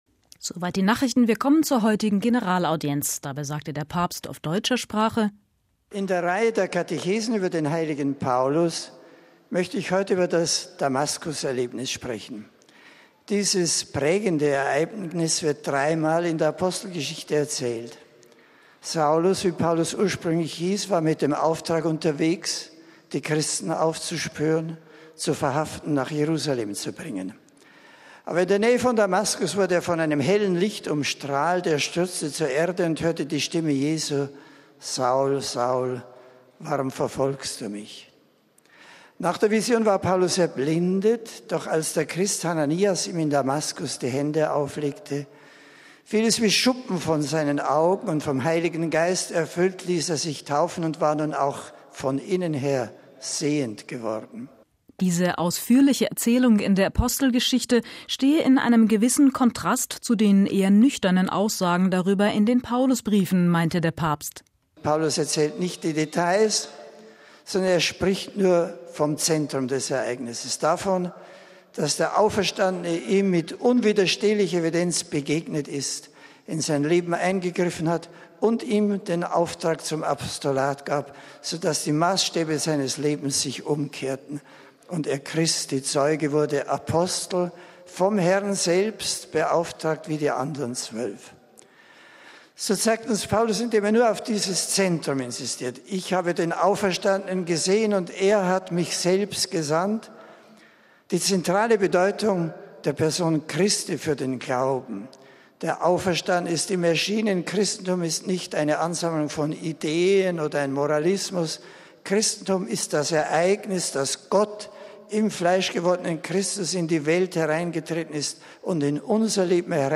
MP3 Das Christentum ist nach den Worten von Papst Benedikt XVI. keine Philosophie oder ein Moralkodex, sondern gründet in der persönlichen Begegnung mit Christus. Auch dem Völkerapostel Paulus sei bei seiner Bekehrung „nicht nur der historische Jesus, sondern der lebendige Christus erschienen“, sagte der Papst bei der Generalaudienz am Mittwoch im Vatikan.
Wie in der Vorwoche stellte Benedikt XVI. die Biografie des Paulus in den Mittelpunkt seiner Ansprache in der Audienzhalle.